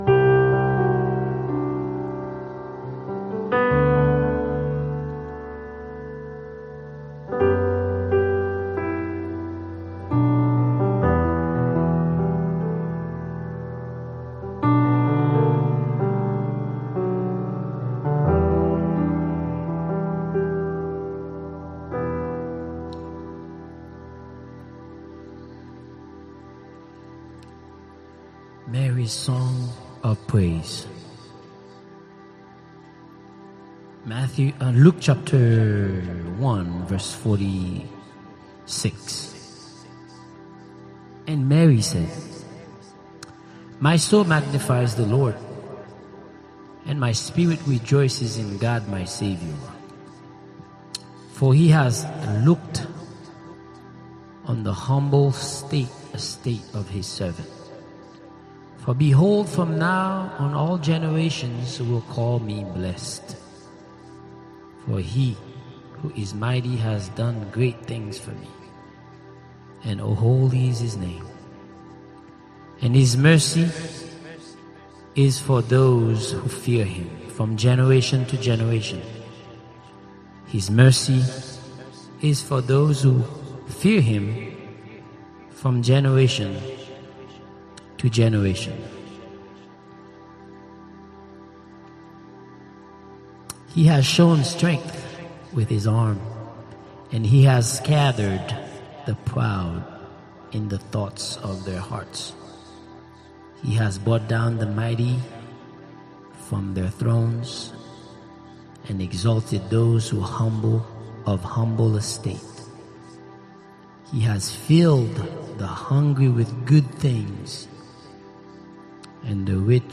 Soaking Prayer and Worship (audio)